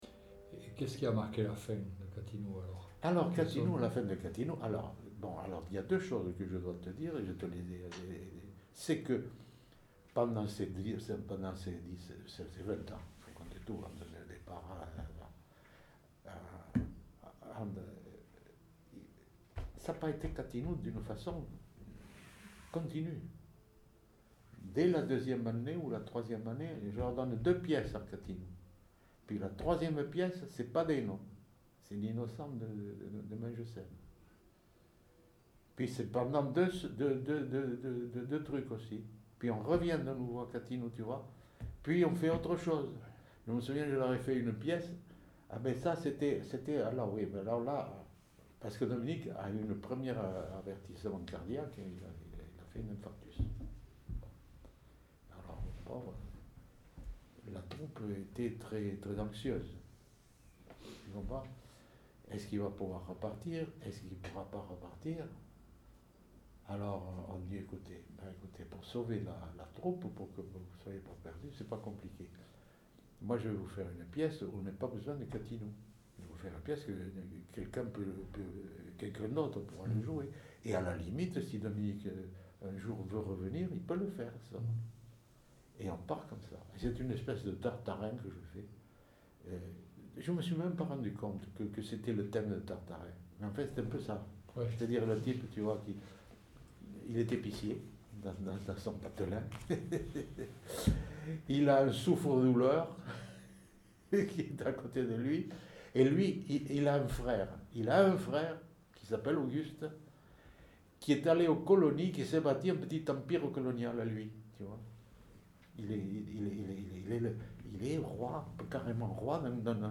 Lieu : Saint-Sauveur
Genre : récit de vie